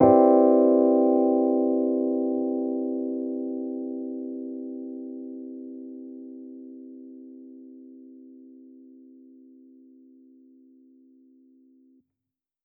Index of /musicradar/jazz-keys-samples/Chord Hits/Electric Piano 2
JK_ElPiano2_Chord-Cmaj9.wav